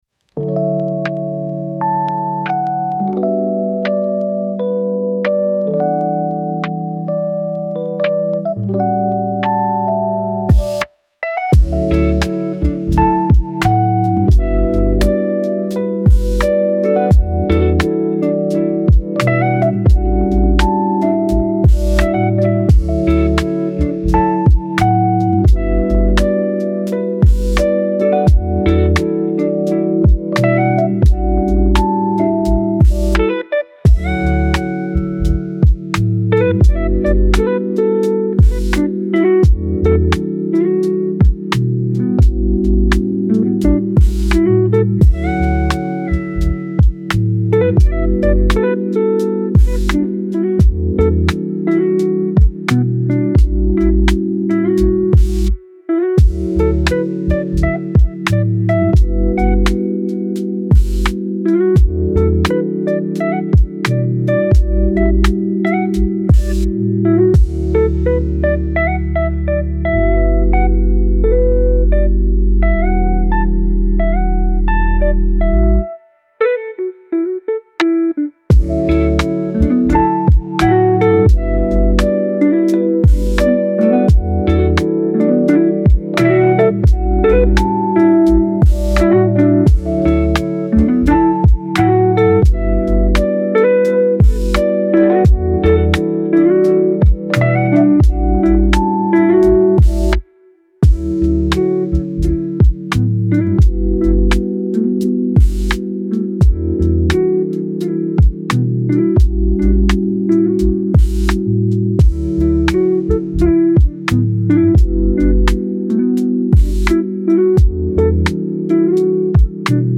Gamma 40 Hz : Focus et Mémoire